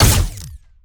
GUNAuto_Plasmid Machinegun B Single_02_SFRMS_SCIWPNS.wav